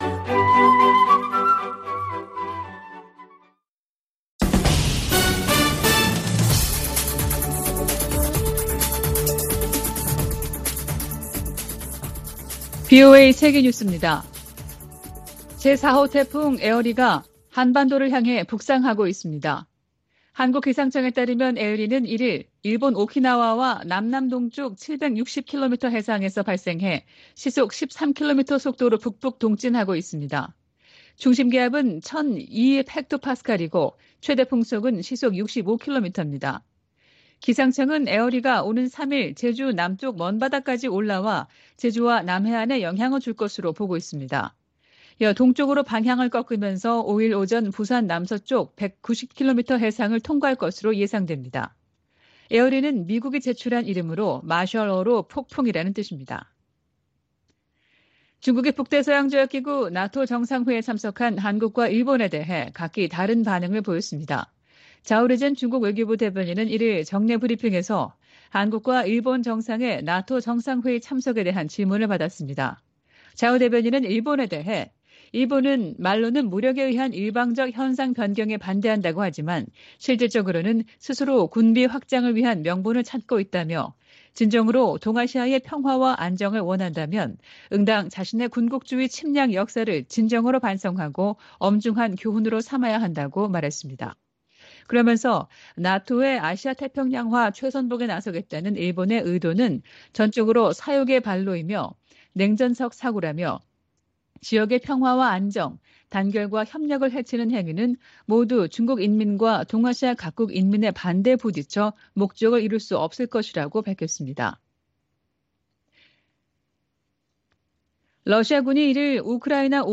VOA 한국어 아침 뉴스 프로그램 '워싱턴 뉴스 광장' 2022년 7월 2일 방송입니다. 북대서양조약기구(NATO·나토) 정상회의가 막을 내린 가운데 조 바이든 미국 대통령은 ‘역사적’이라고 평가했습니다. 미국의 한반도 전문가들은 윤석열 한국 대통령이 나토 정상회의에서 북핵 문제에 대한 미한일 3각협력 복원 의지를 분명히했다고 평가했습니다. 미 국무부가 미일 동맹 현대화와 미한일 삼각공조 강화 등 일본 전략을 공개했습니다.